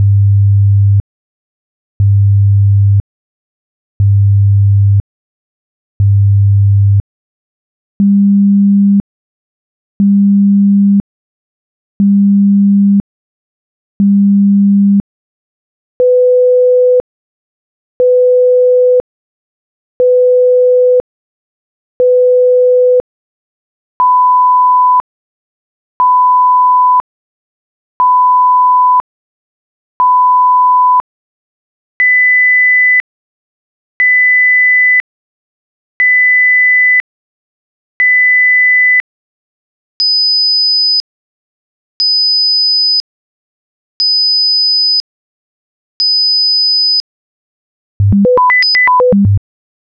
Atlas - STest1-Pitch-100,200,500,1000,2000,5000.flac